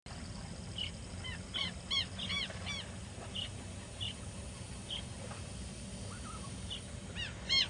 Pectoral Sandpiper